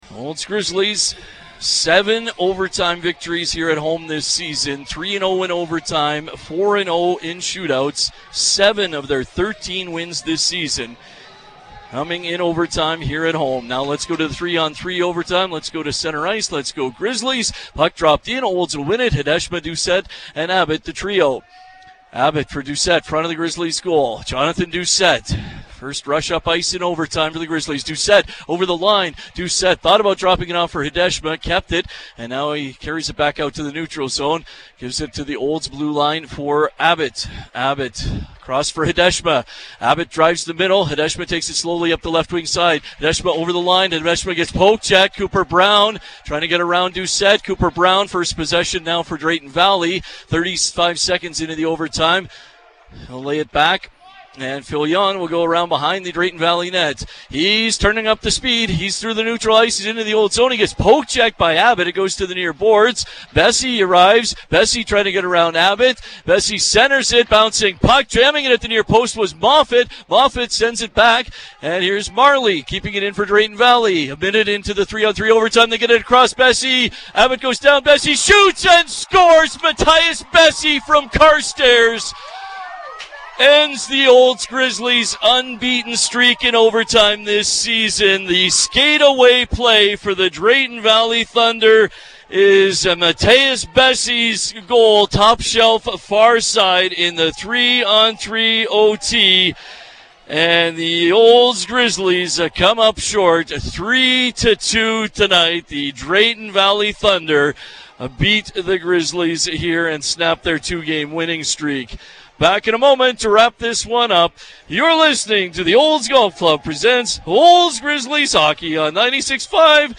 As heard on 96.5 CKFM, a kid from Carstairs came up clutch for Drayton Valley in Olds.